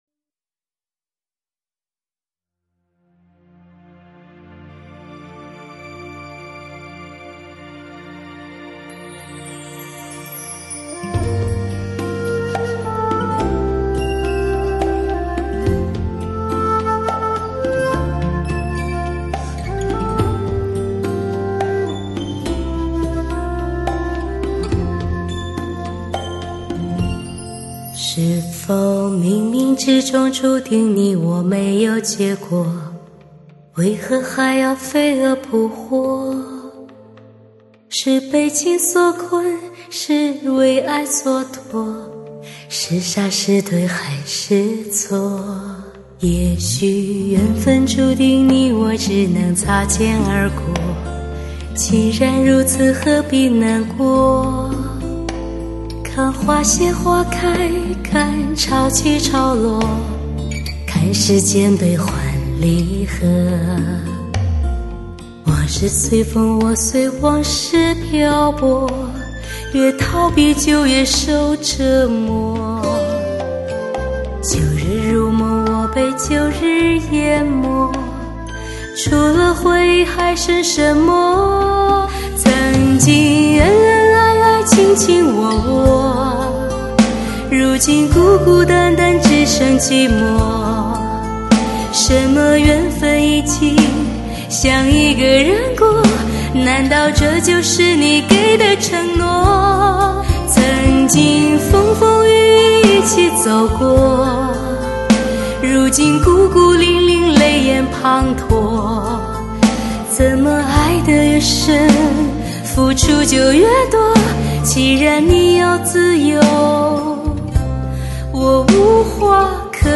清新音色，磁性迷人歌喉，一声声，一首